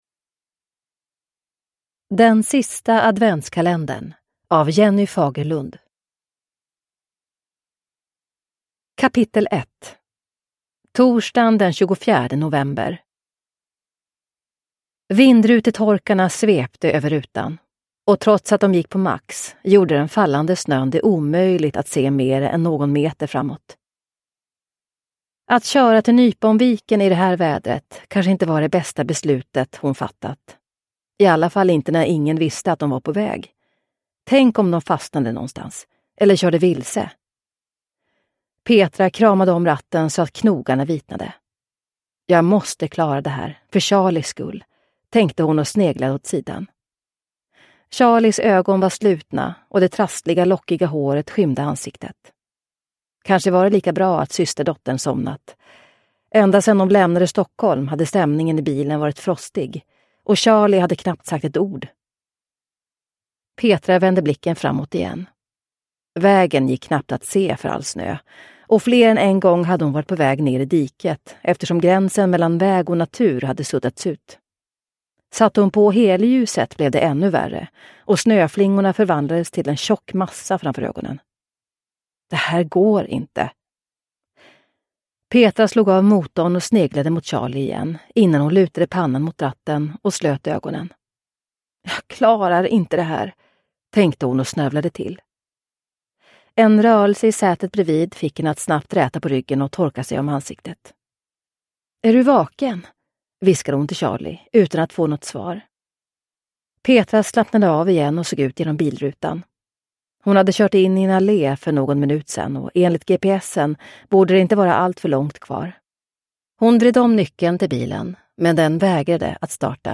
Den sista adventskalendern – Ljudbok